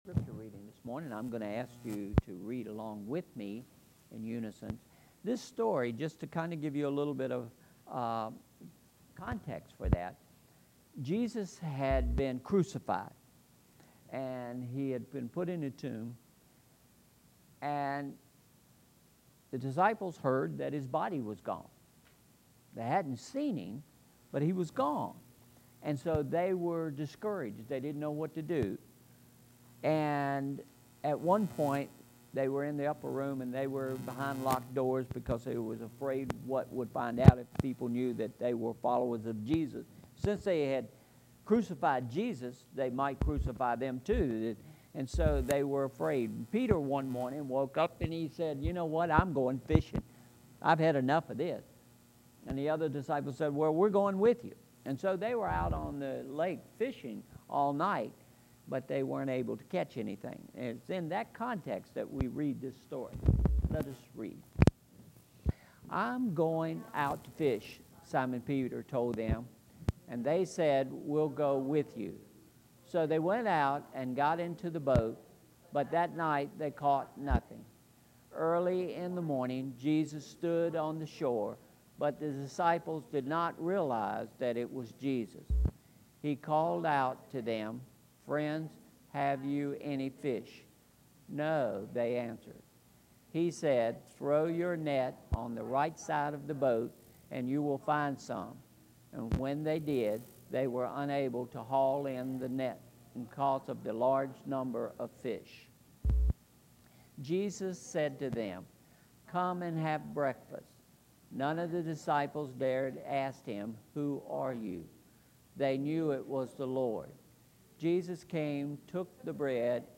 Sermon Title: “Do You Love Me?”